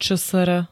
ČSR [čé es er] skr. i ž. ▶ Československá republika: prvá ČSR
Zvukové nahrávky niektorých slov